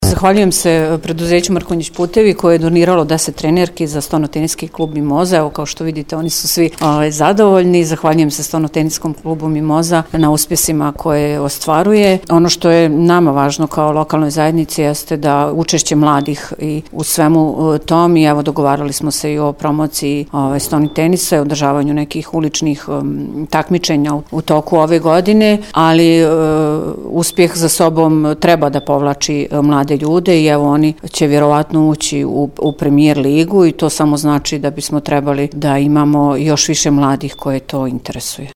izjavu
DIVNA-ANICIC-NACELNIK-SPORT.mp3